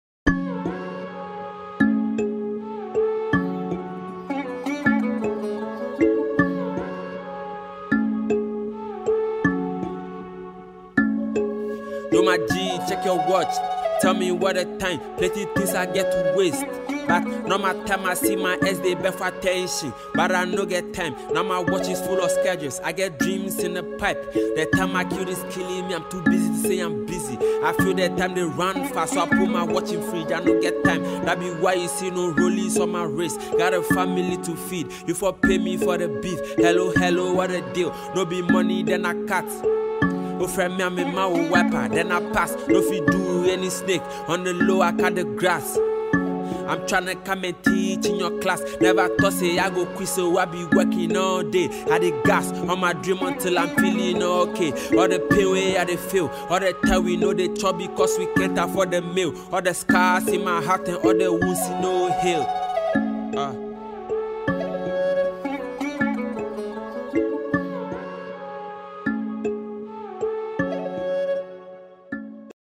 Ghana Music Music
Ghanaian singer and songwriter
This Ghana mp3 and freestyle song is really a banger.